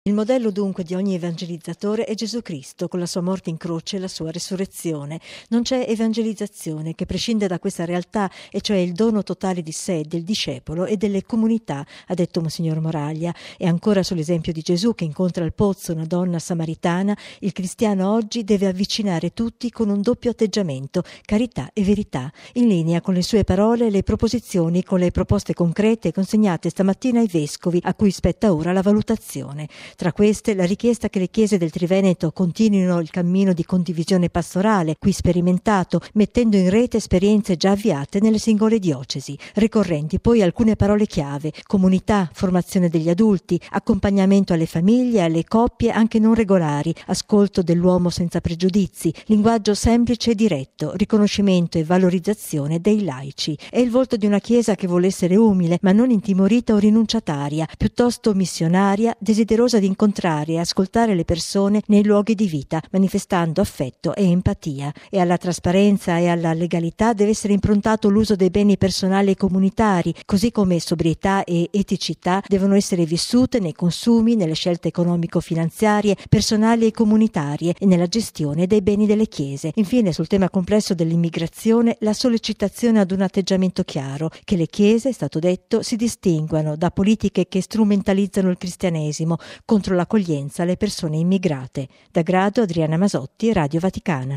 Il servizio della nostra inviata